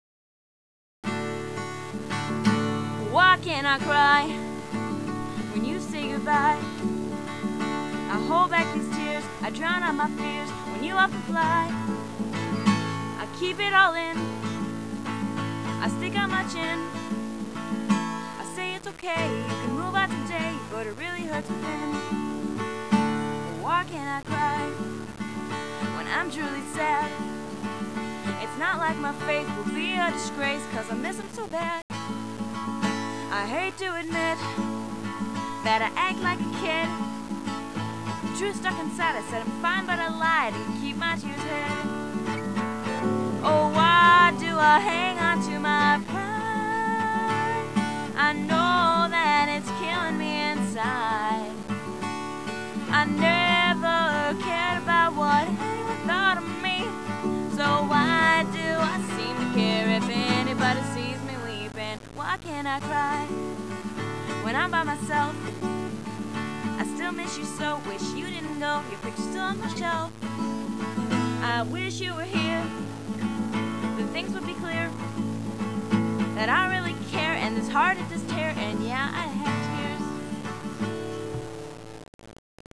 Hopefully updating this sometime soon (got the software and mic, just need to get off my butt and get it going) Once again sorry the sound quality is insanely bad.
This is one of my favorites, but it's hard for me to play, which makes it sound kinda bad.